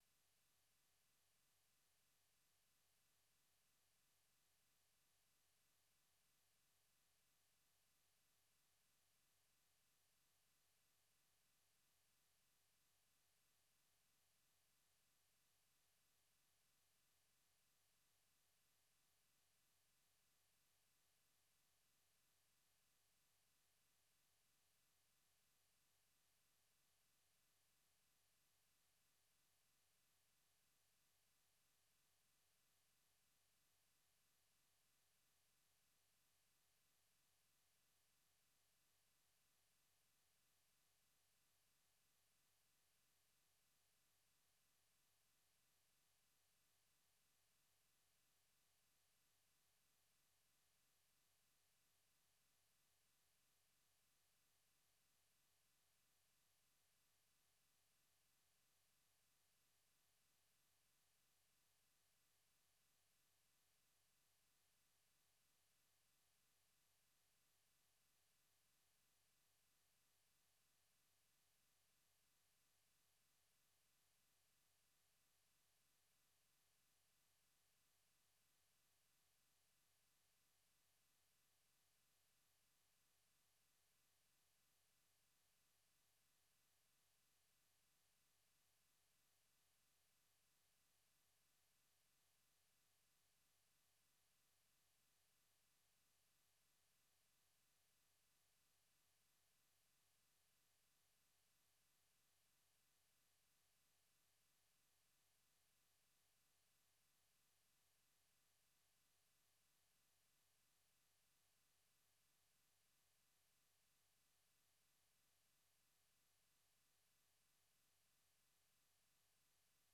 Idaacadda Fiidnimo ee Evening Edition waxaad ku maqashaan wararkii ugu danbeeyey ee Soomaaliya iyo Caalamka, barnaamijyo iyo wareysiyo ka turjumaya dhacdooyinka waqtigaasi ka dhacaya daafaha Dunida.